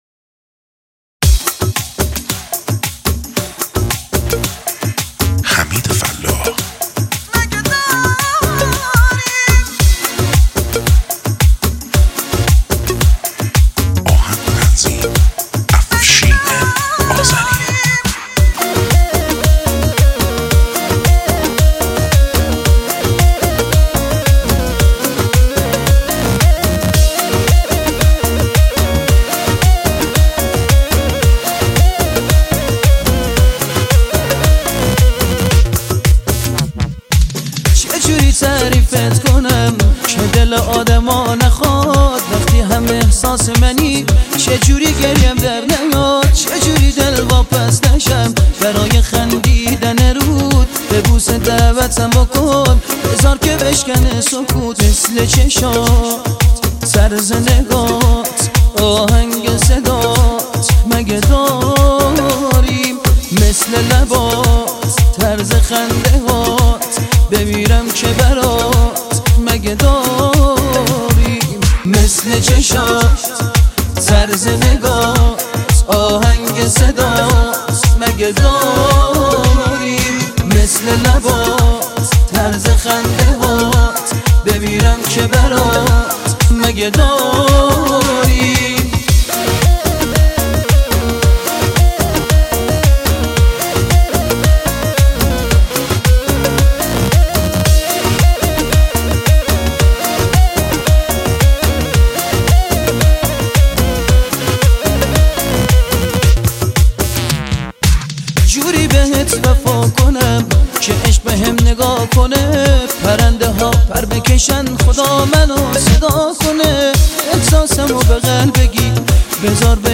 ریمیکس هوش مصنوعی